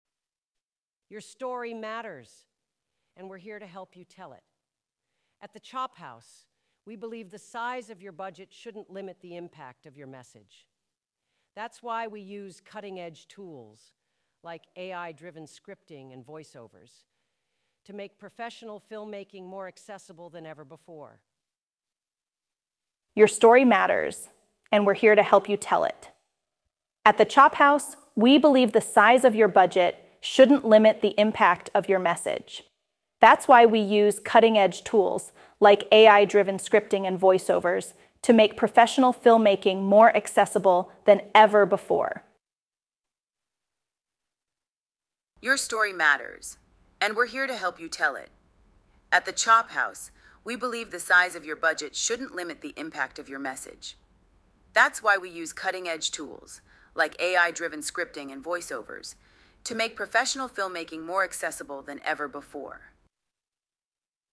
This is a new feature that lets you design a voice by prompt. You get three options. You can hear the quality and volume levels vary between voices. I left the audio raw to highlight these differences.
Voice by prompt sample
by Eleven Labs